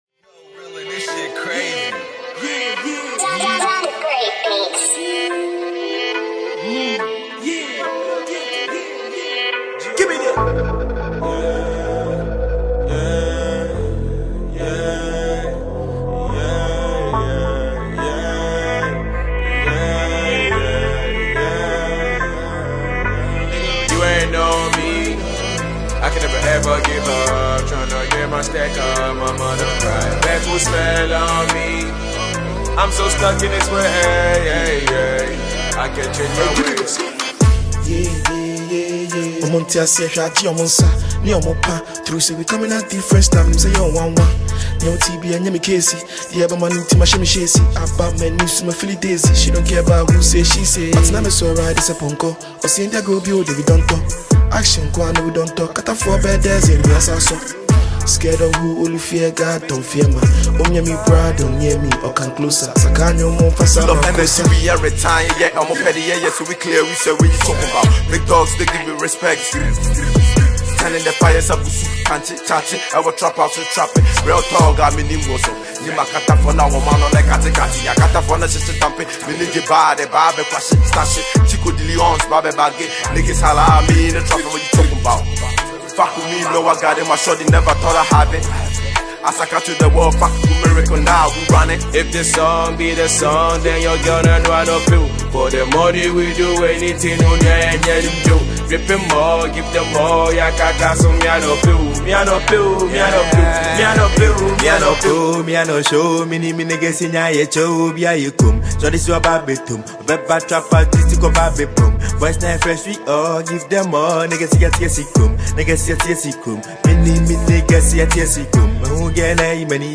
Ghana Music
Ghanaian Kumerica rapper
Kumerica rappers